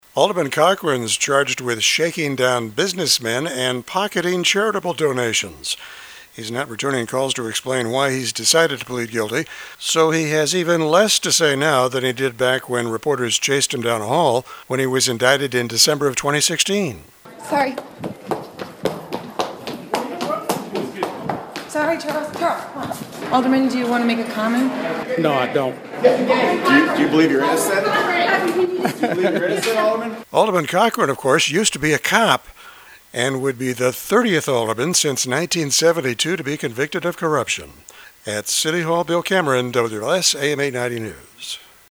He’s not returning calls to explain why he’s decided to plead guilty, so he has even less to say now than he did when reporters chased him down a hall when he was indicted in December of 2016.